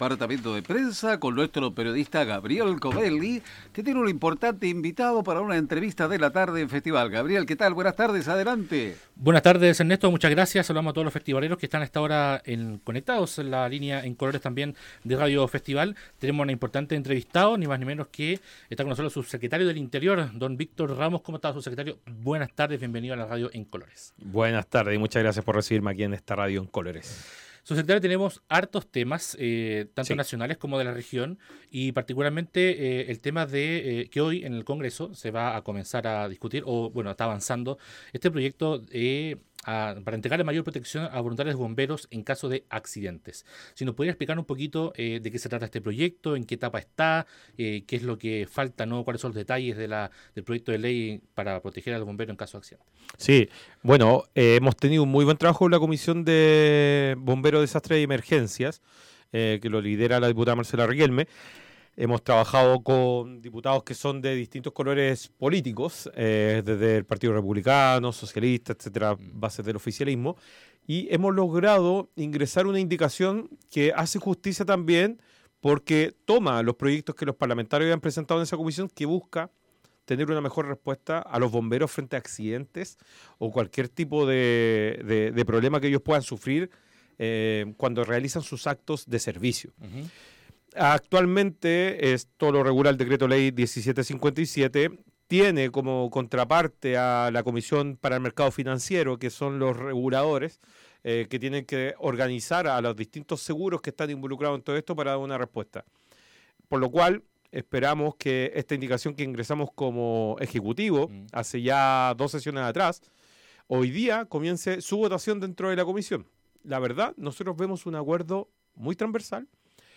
El Subsecretario del Interior Víctor Ramos estuvo en los estudios de Radio Festival para abordar distintos temas de contingencia, como además el proyecto de ley que da mayor protección a los voluntarios de bomberos